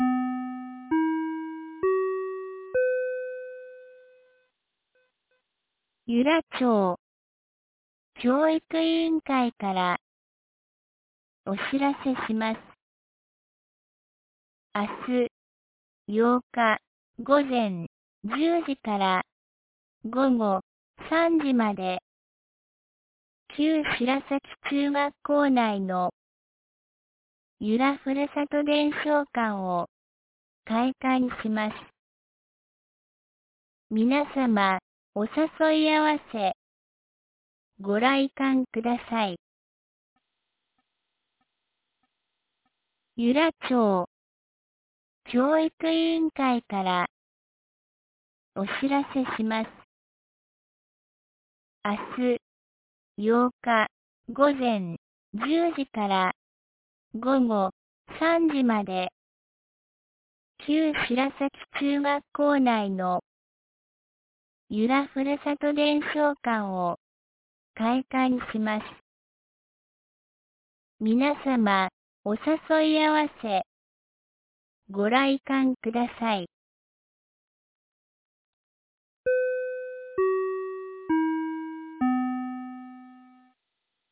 2025年11月07日 17時12分に、由良町から全地区へ放送がありました。